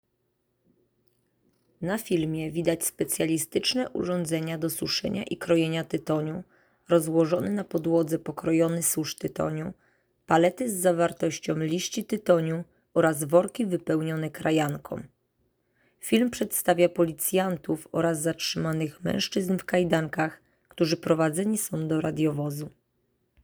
Nagranie audio audiodeskrypcja.m4a